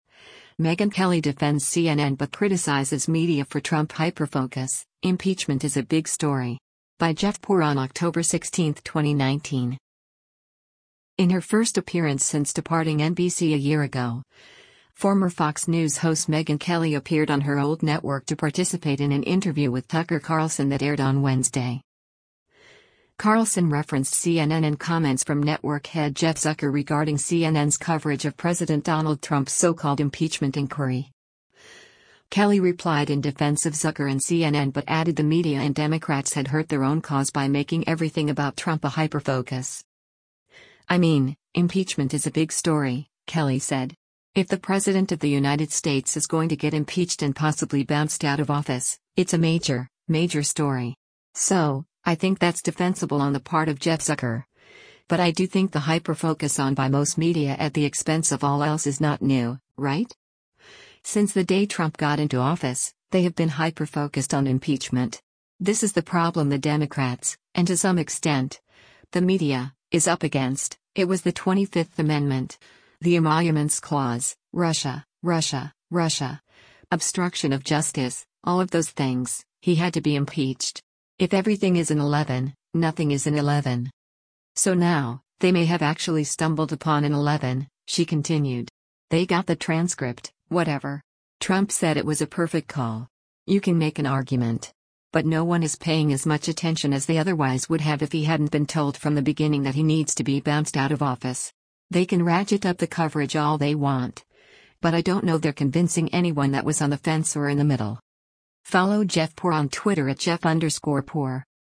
In her first appearance since departing NBC a year ago, former Fox News host Megyn Kelly appeared on her old network to participate in an interview with Tucker Carlson that aired on Wednesday.